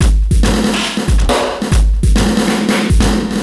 E Kit 26.wav